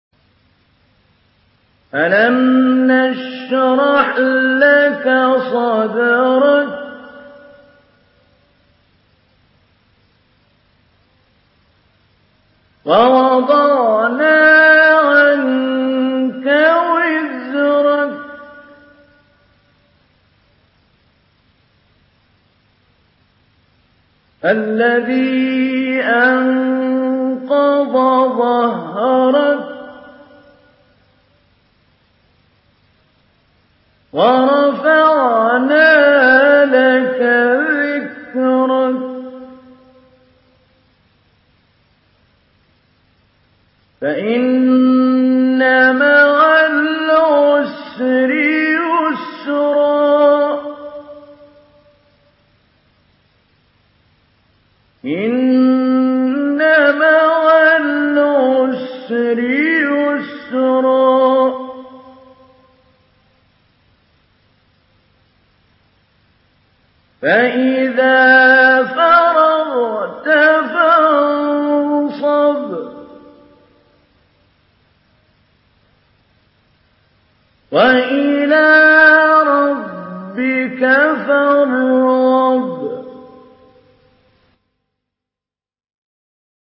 Surah الشرح MP3 in the Voice of محمود علي البنا مجود in حفص Narration
Surah الشرح MP3 by محمود علي البنا مجود in حفص عن عاصم narration.